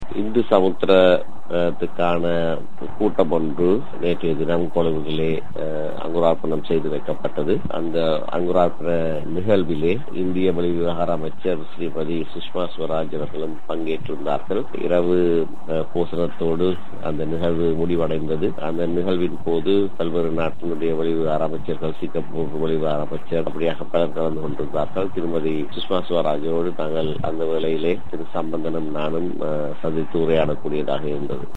இவை குறித்த விபரங்களை கூட்டமைப்பின் பேச்சாளரும், நாடாளுமன்ற உறுப்பினருமான எம்.ஏ.சுமந்திரன்.